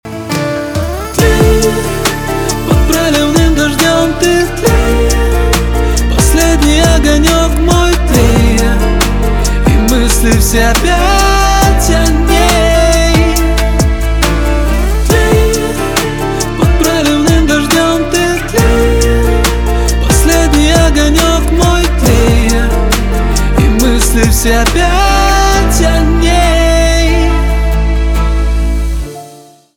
поп
грустные , гитара , битовые , басы , спокойные